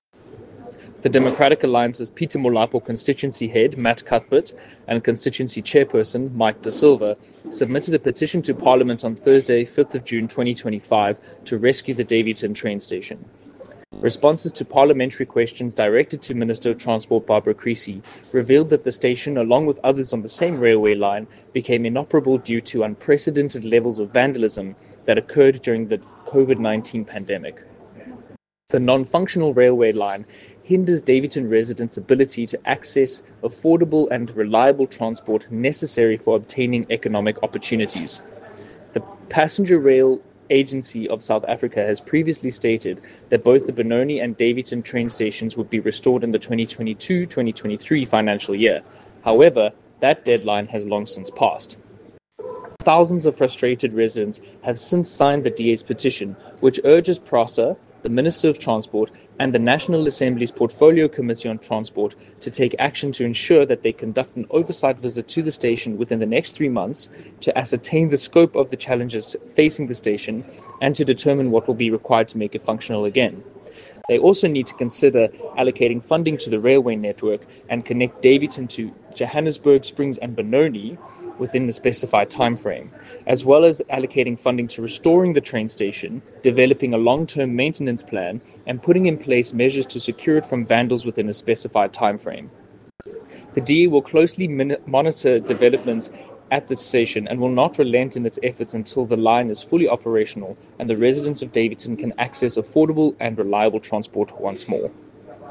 Note to Editors: Please find an English soundbite by Cllr Mike da Silva